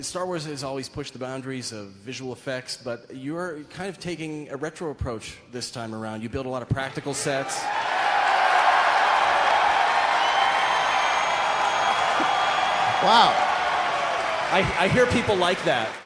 I feel like this excerpt of audience applause from a Star Wars 7 announcement sums up our collective desire to return to a more human-centered, physically based [thinking] technology.
PracticalEffects_JJAbrams_StarWars7.wav